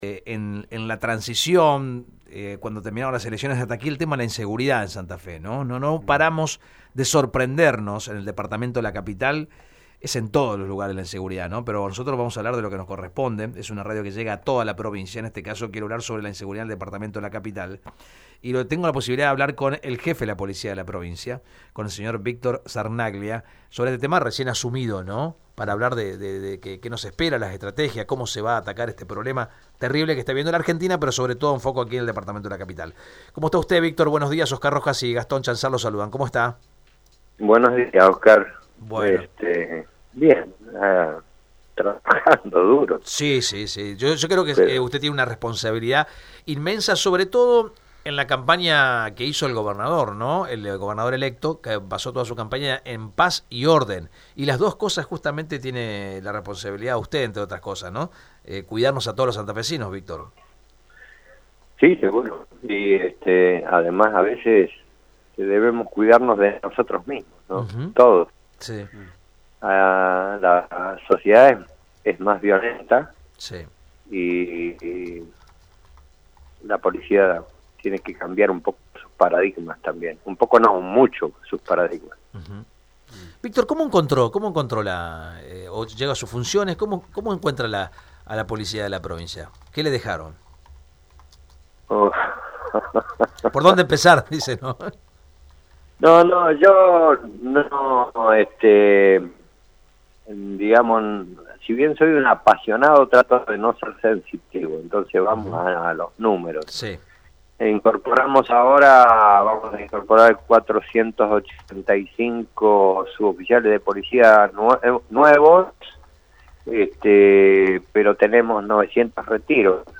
En dialogo con Radio EME, Víctor José Sarnaglia se refirió a la actualidad del sistema de seguridad de la provincia de Santa Fe, y también sobre lo que encontró tras asumir a su cargo.